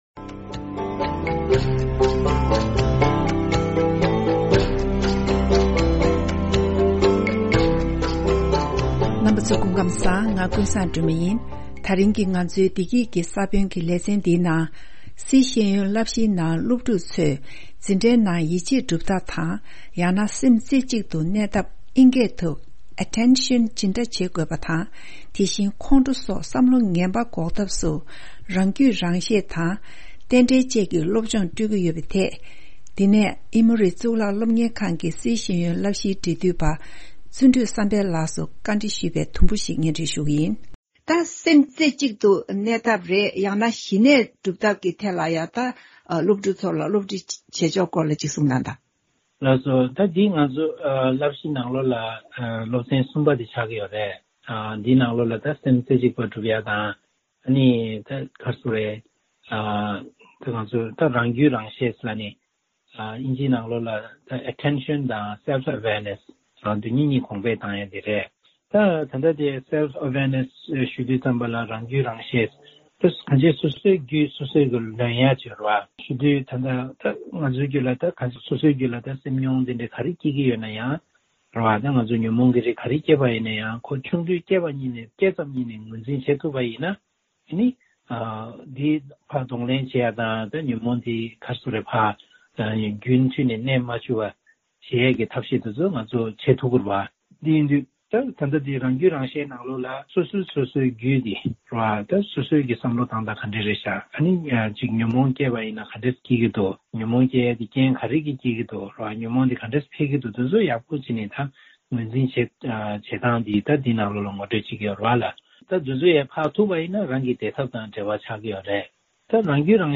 བཅར་འདྲི་ཞུས་པའི་ཚན་པ་ཞིག་གསན་རོག་གནང་།།